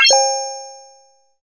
addScore.mp3